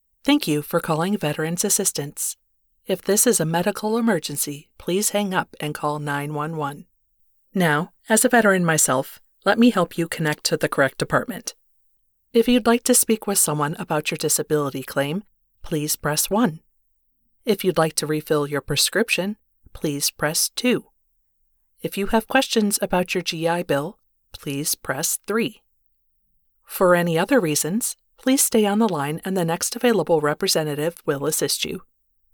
Phone System
North American (General), North American - US Gen American
VA phone tree.mp3